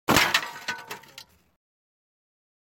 Sächsische Schweiz » A lot of branches are breaking, close up, H6
描述：Recorded in the "sächsische Schweiz".Recorded with a Zoom H6 (Mic: XYH6) 41khz/16bit
标签： rottenwood cracking old snap crack branch pileofbranches pile wood break ZoomH6 trees snapping breaking bough tree wooden sticks branches closeup
声道立体声